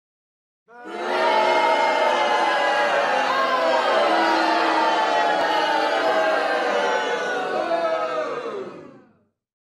BOOING_crowd.mp3